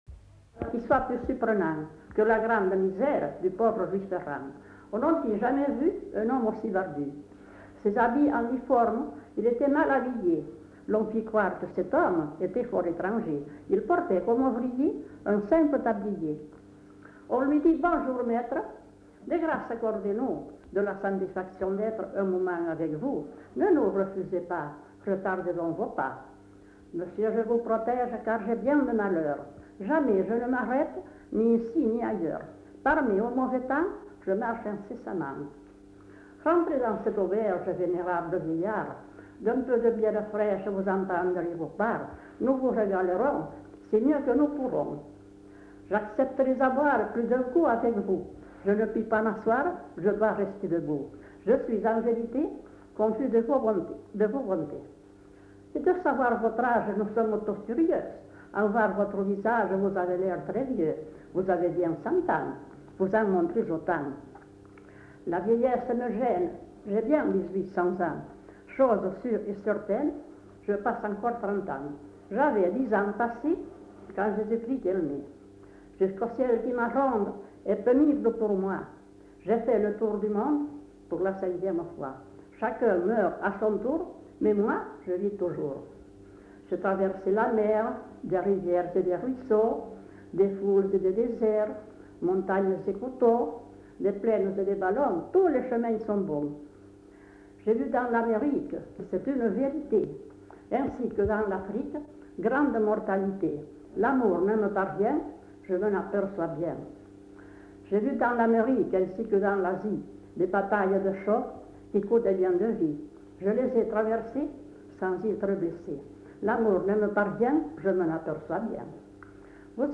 Lieu : [sans lieu] ; Landes
Genre : conte-légende-récit
Type de voix : voix de femme
Production du son : récité
Classification : monologue